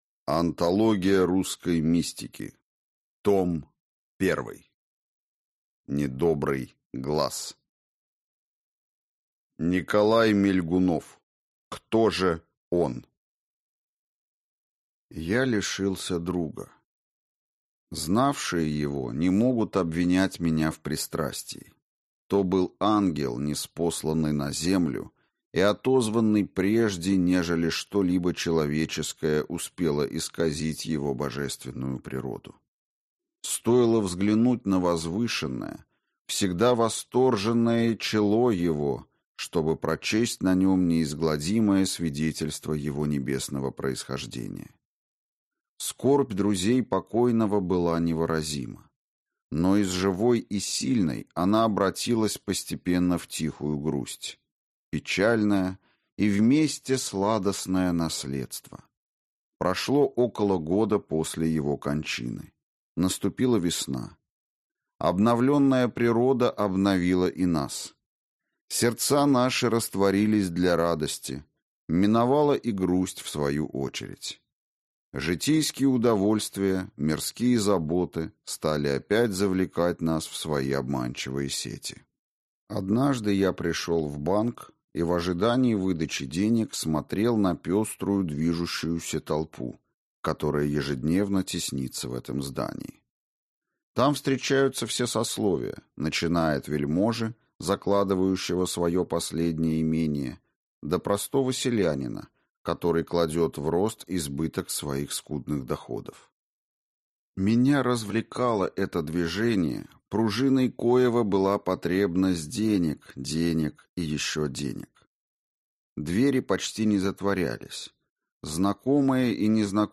Аудиокнига Недобрый глаз | Библиотека аудиокниг
Прослушать и бесплатно скачать фрагмент аудиокниги